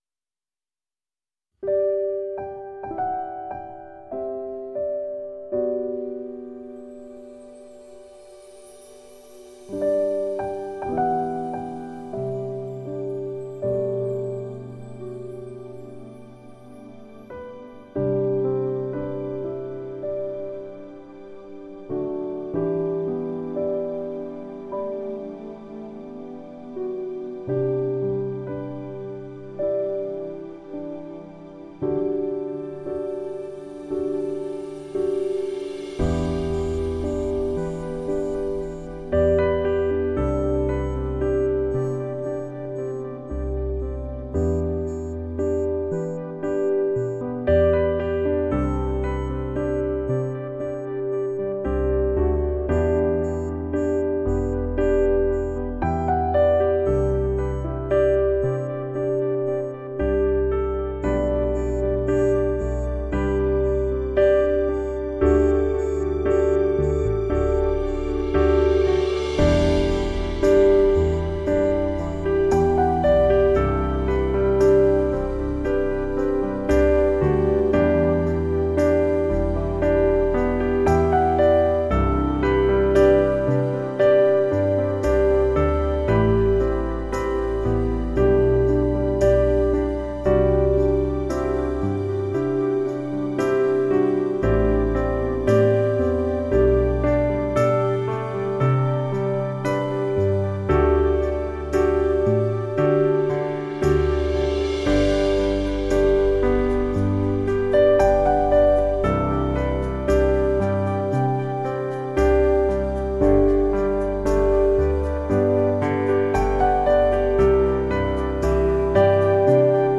Трогательный момент: музыка для признания в любви